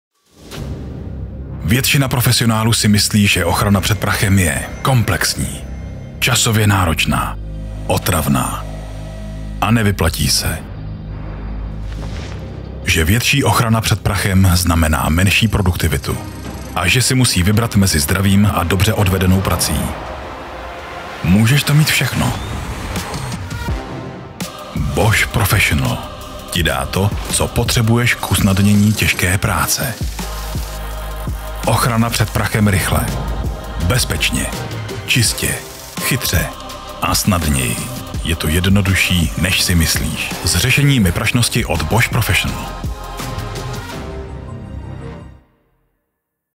Součástí každého jobu je i základní postprodukce, tedy odstranění nádechů, filtrování nežádoucích frekvencí a ekvalizace a nastavení exportu minimálně 48kHz/24bit, okolo -6dB, jestli se nedohodneme jinak.
Mužský voiceover - hlas do krátkých reklamních spotů!